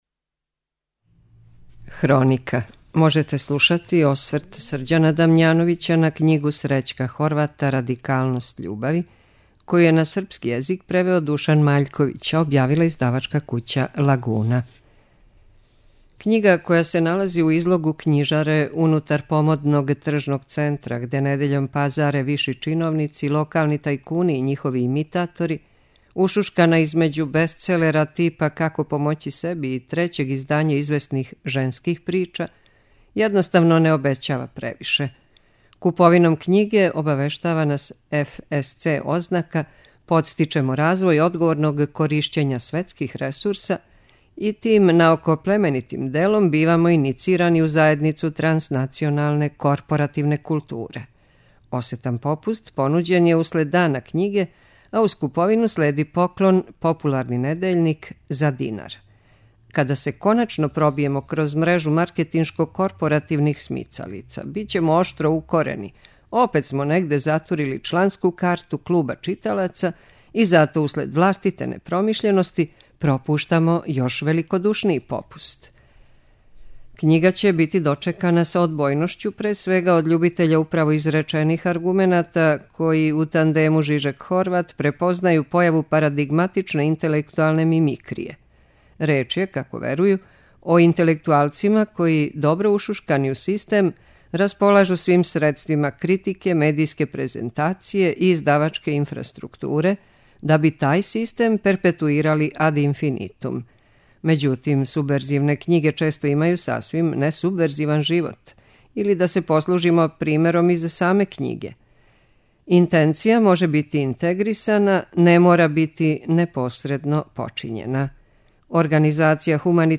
Прочитаћемо осврт